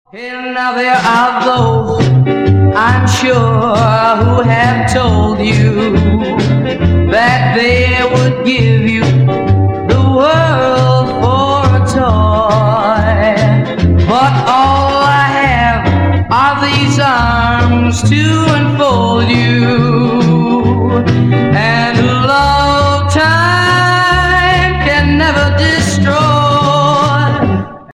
Rock
60-е
РОК-Н-РОЛЛ
Блюз-рок
Замечательная ретро композиция